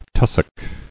[tus·sock]